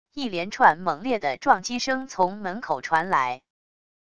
一连串猛烈的撞击声从门口传来wav音频